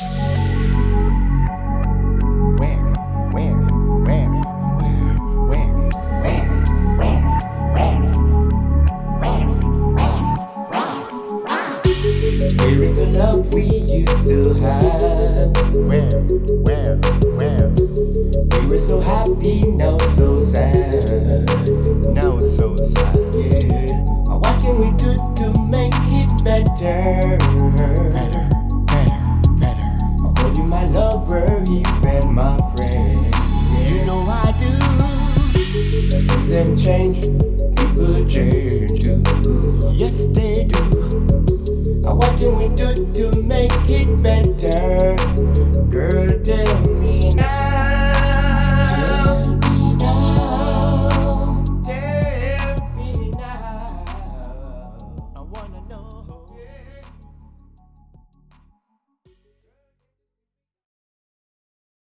Here are some tracks from the studio that we hope you enjoy.